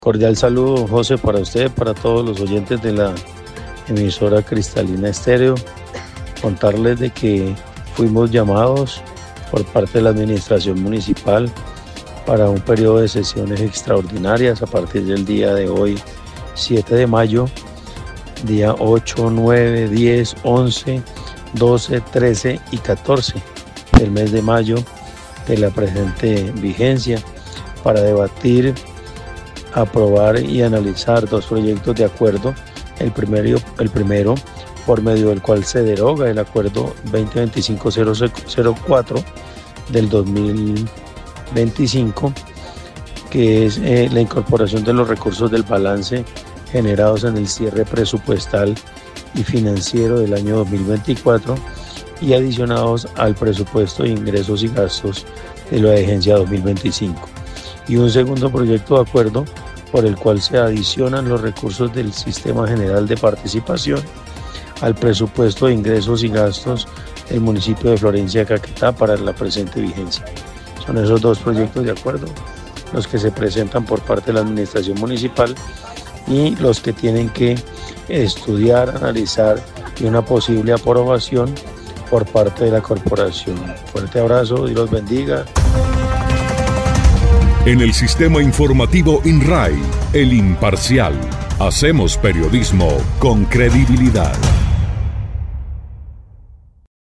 Así lo dio a conocer el presidente del concejo, el conservador, Mauricio Torres Montes, quien dijo que, los dos proyectos de acuerdo tienen que ver con recursos que pretenden ser adicionados al presupuesto de la vigencia 2025, unos por cierre fiscal y otros procedentes del sistema general de participaciones.
01_CONCEJAL_MAURICIO_TORRES_EXTRAS.mp3